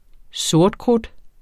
Udtale [ ˈsoɐ̯dˌkʁud ]